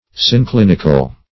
Synclinical \Syn*clin"ic*al\, a.